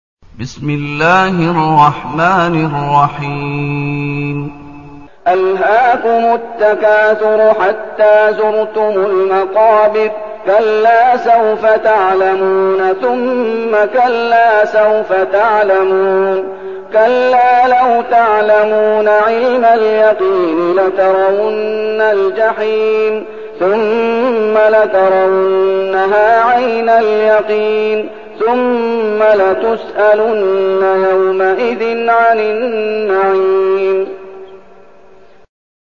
المكان: المسجد النبوي الشيخ: فضيلة الشيخ محمد أيوب فضيلة الشيخ محمد أيوب التكاثر